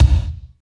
31.05 KICK.wav